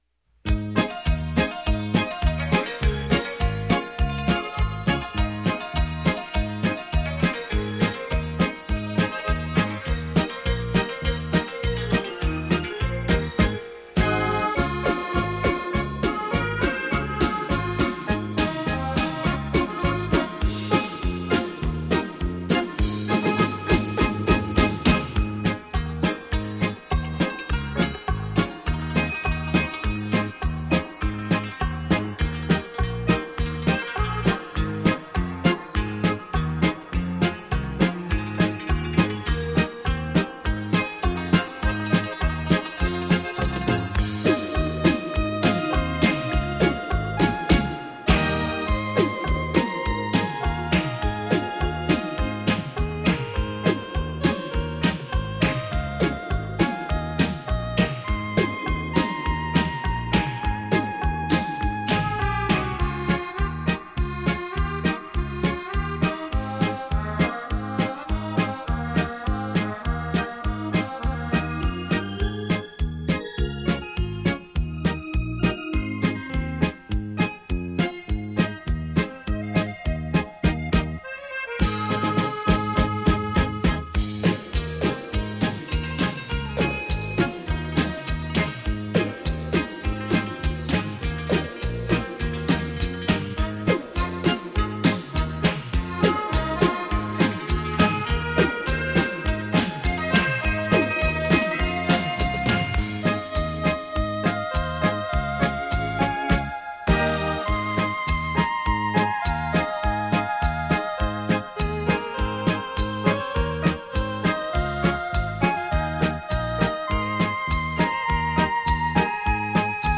以中西乐器及电声乐器配器演绎的中外名曲！有许多首曲子前后加了一些效果声环绕特强，望各位喜欢！